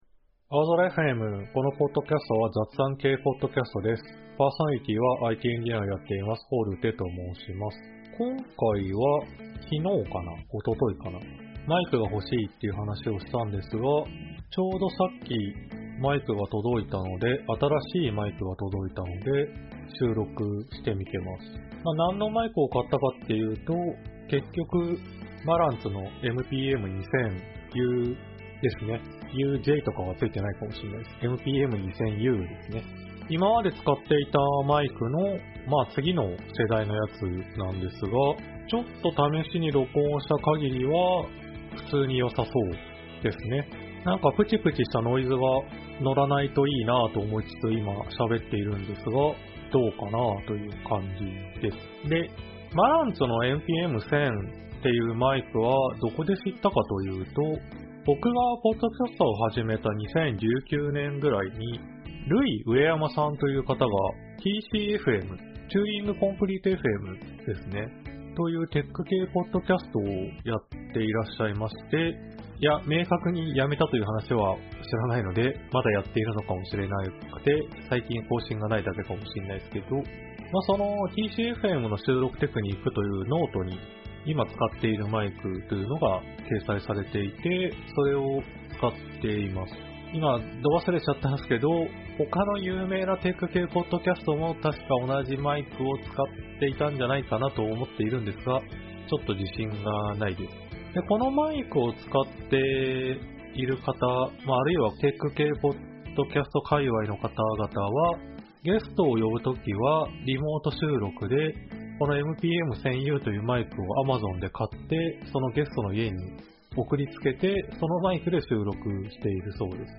aozora.fmは仕事や趣味の楽しさを共有する雑談系Podcastです。
新しいマイクを買った最初の収録